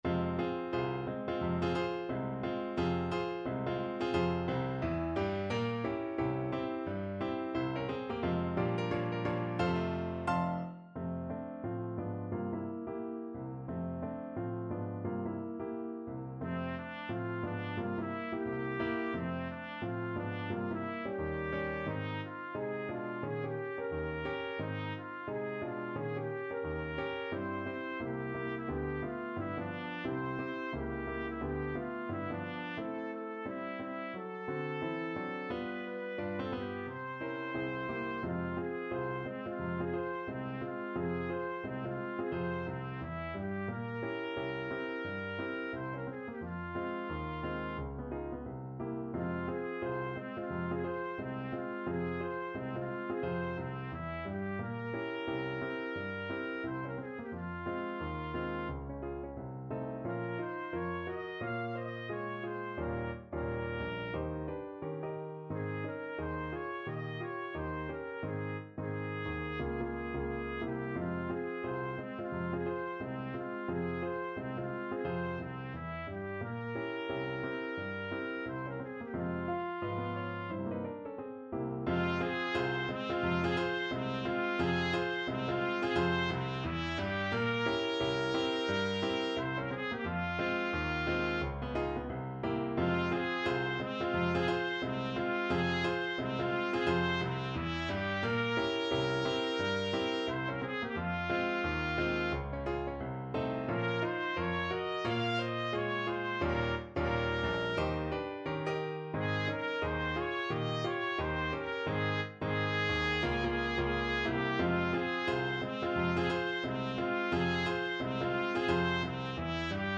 Trumpet version
~ = 176 Moderato
2/2 (View more 2/2 Music)
C5-F6
Jazz (View more Jazz Trumpet Music)
Rock and pop (View more Rock and pop Trumpet Music)